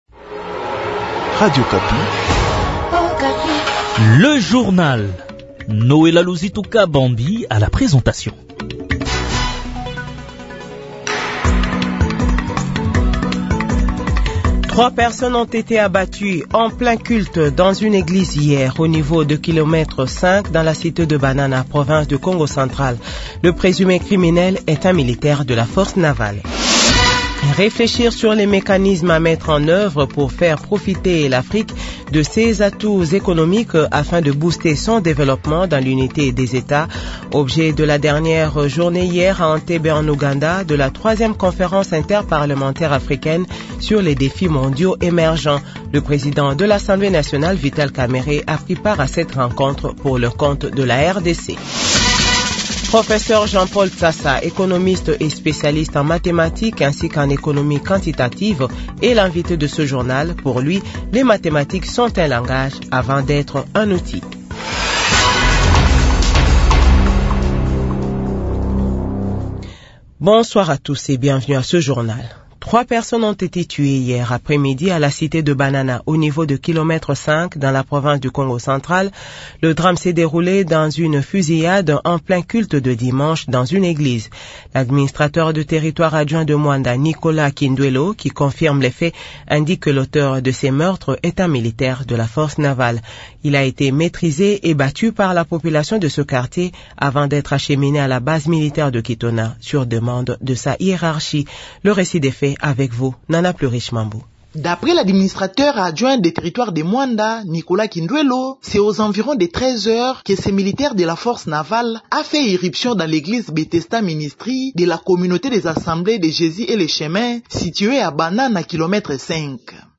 Journal 18h00